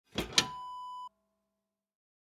Metal Hatch Open Wav Sound Effect #2
Description: The sound of opening a small metal hatch
Properties: 48.000 kHz 24-bit Stereo
A beep sound is embedded in the audio preview file but it is not present in the high resolution downloadable wav file.
Keywords: metal, metallic, iron, small, fireplace, furnace, oven, door, hatch, open, opening
metal-hatch-open-preview-2.mp3